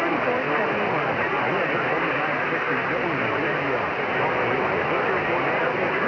I definitely hear "960 WDER".